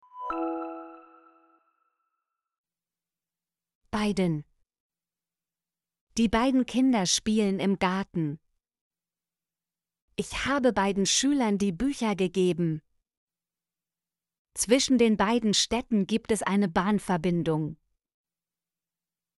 beiden - Example Sentences & Pronunciation, German Frequency List